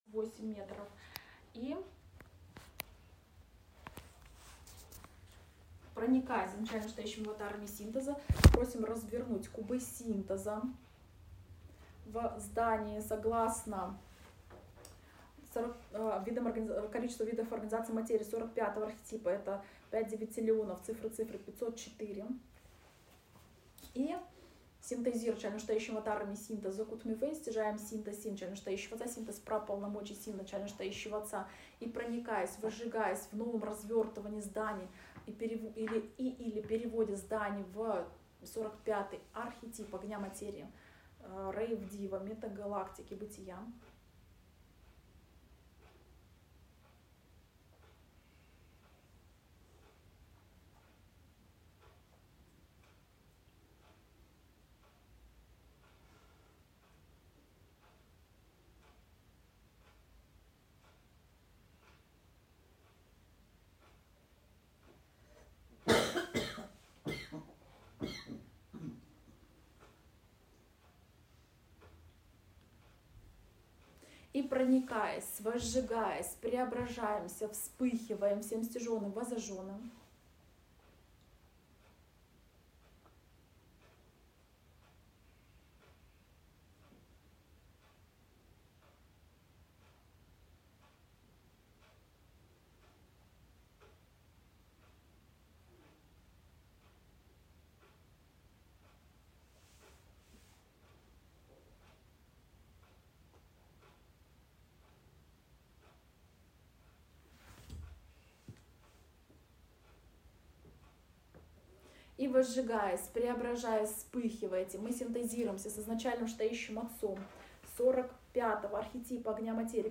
• Первый аудио файл получился из двух частей, был сбой в записи.